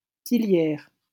Tillières (French pronunciation: [tiljɛʁ]